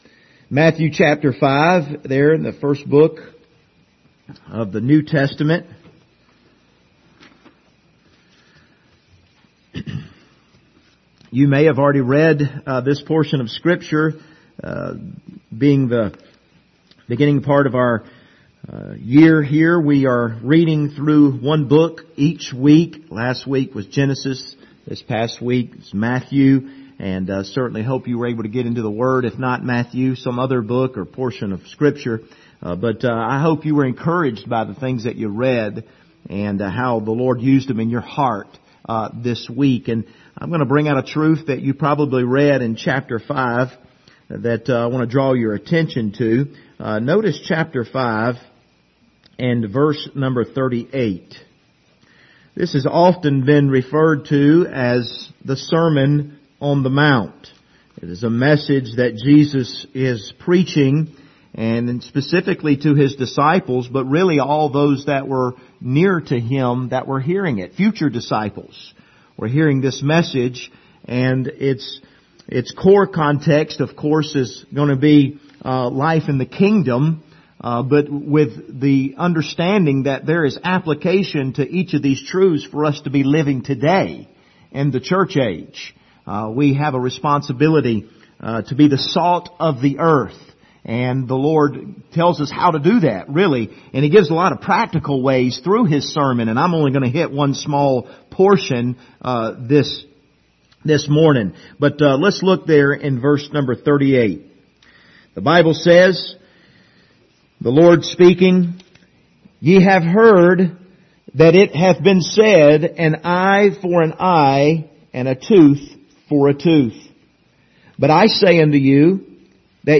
Passage: Matthew 5:38-42 Service Type: Sunday Morning